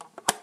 close lid empty.aiff